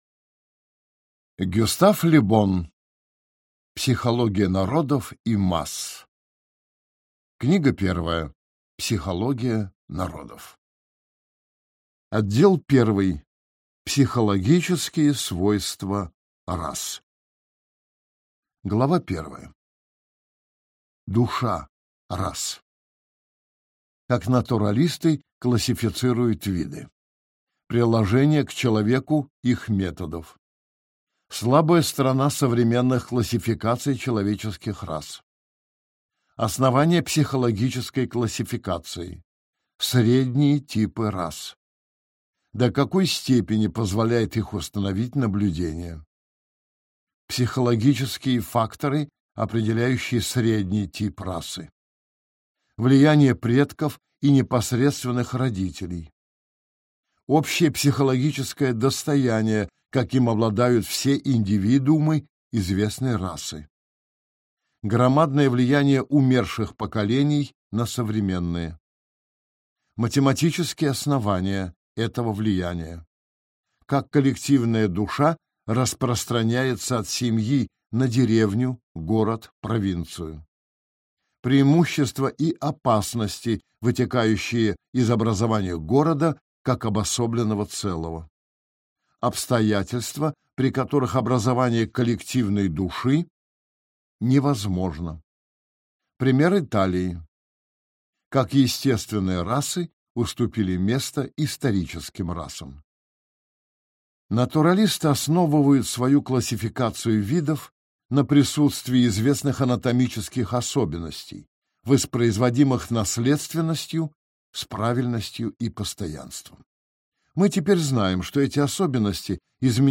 Аудиокнига Психология народов и масс | Библиотека аудиокниг